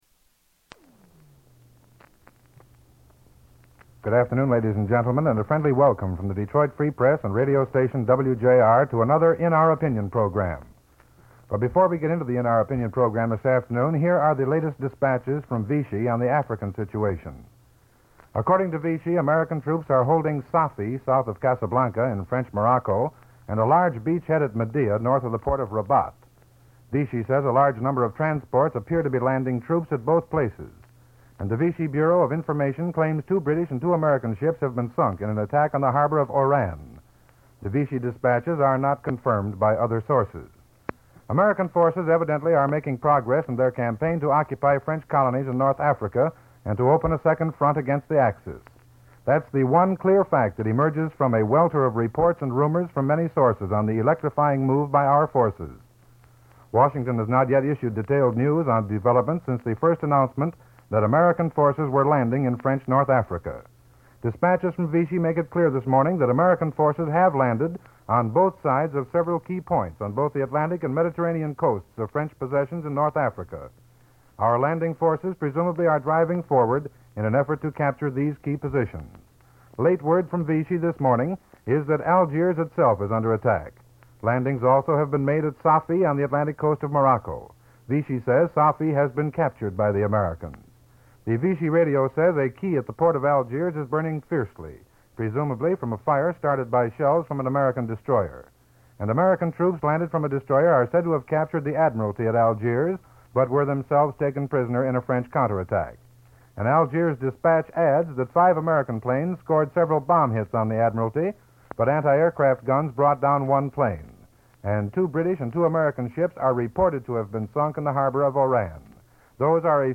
1942-11-08: In Our Opinion radio program: roundtable discussion of gasoline rationing in Detroit · Omeka at Auburn